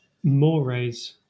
/ˈmɔːɹeɪz/ (audio file from Wiktionary)